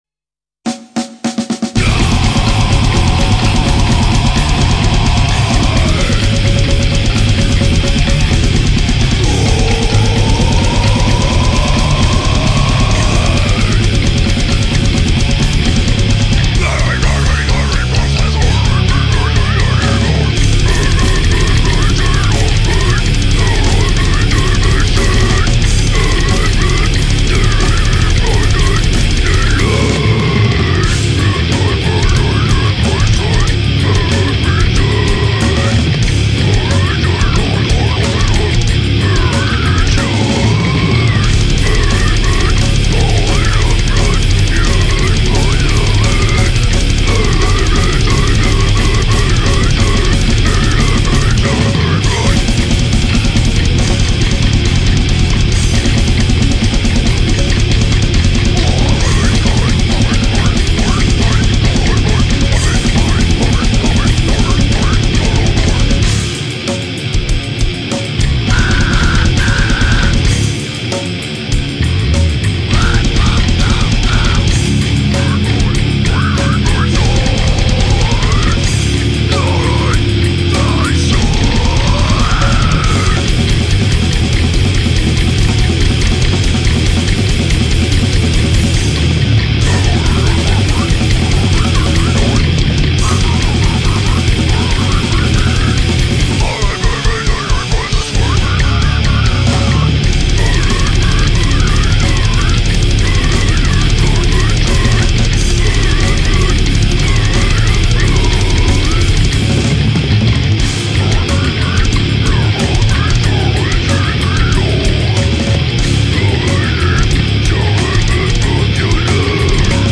et un bon CD deathcore engagé